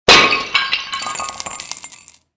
Title=vaisselle_casse